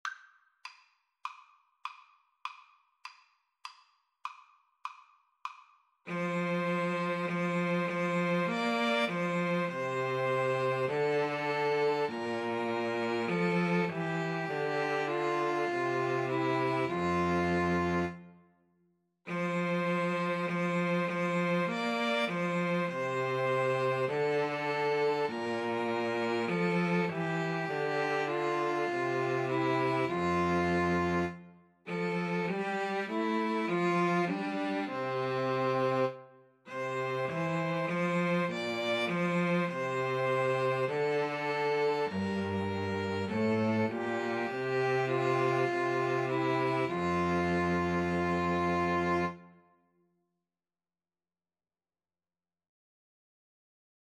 Violin 1Violin 2Cello
4/4 (View more 4/4 Music)
F major (Sounding Pitch) (View more F major Music for 2-Violins-Cello )